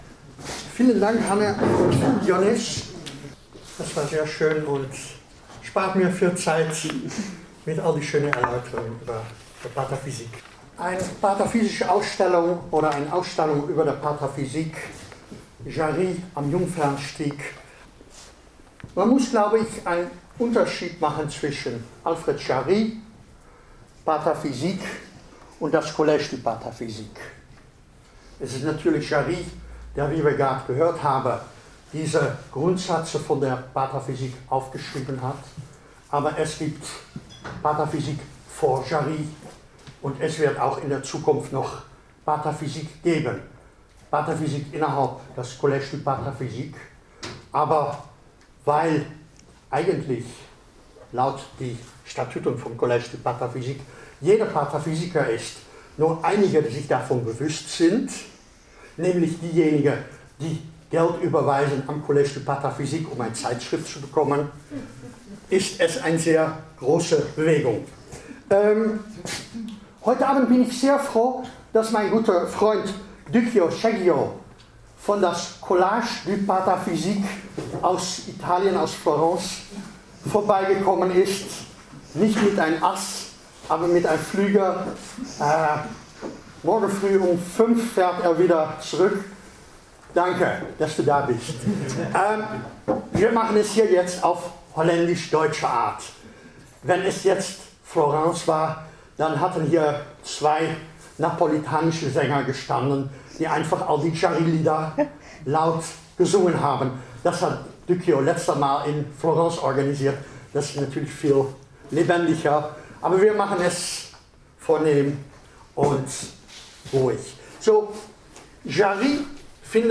Bevor ihre Reise in die Uneindeutigkeit der imaginären Lösungen weiterging, gab es am 2.2.2020 noch einen letzten Haltepunkt im 8. Salon: eine Finissage mit Lesung, Führung und Interview.